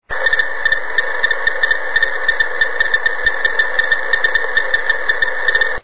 China 2 telemetry on 19.995 MHz The signal consists of a train of pulses.
The word length is 0.3125 sec.
There are 16 such word intervals in a "frame" that lasts 5.0 seconds.
This type of telemetry is called PPM-AM (Pulse-Position Modulated Amplitude Modulation).